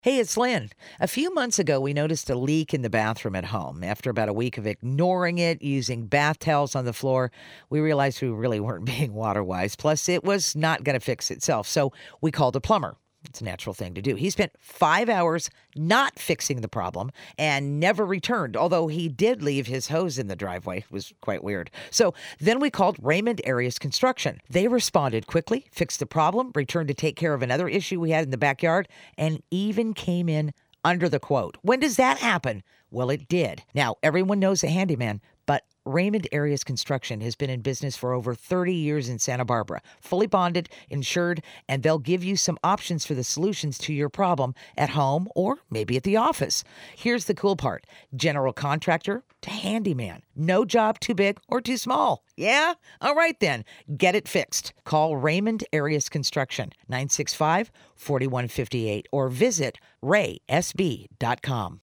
KTDY Radio ad - 1 minute